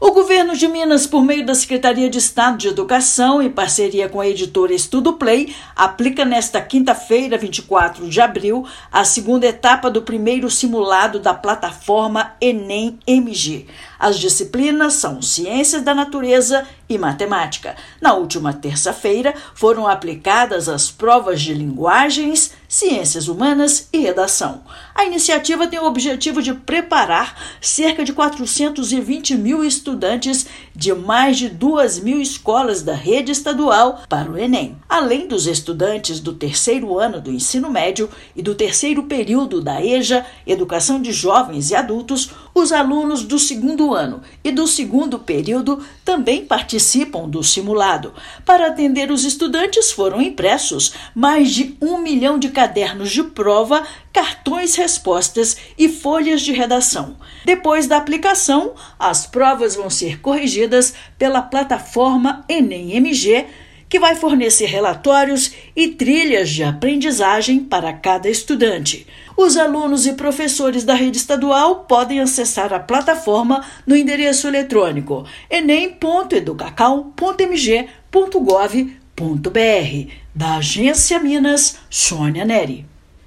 Na terça-feira (22/4), foram realizadas as provas de Linguagens, Ciências Humanas e Redação. Ouça matéria de rádio.